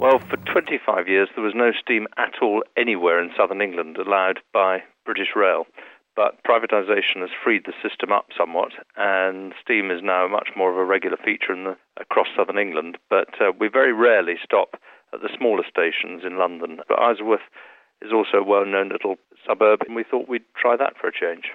Steam Train arrives in Isleworth